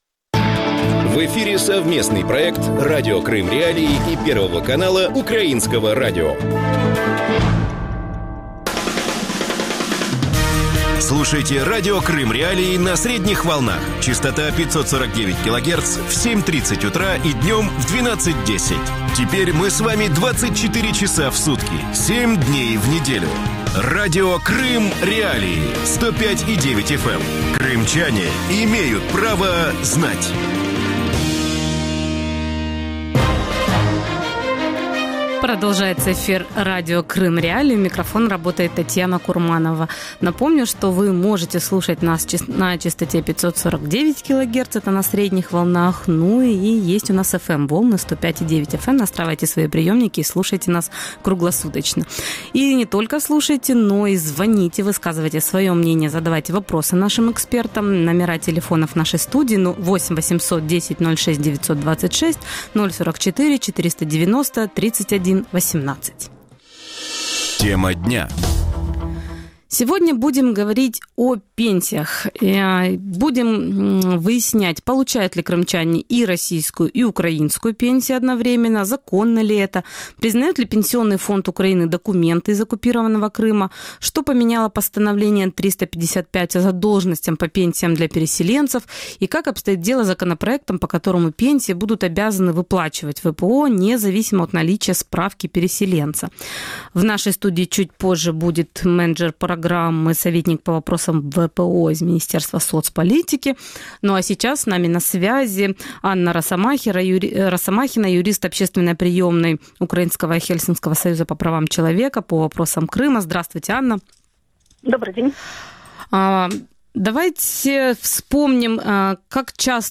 Радио Крым.Реалии вещает 24 часа в сутки на частоте 105.9 FM на северный Крым.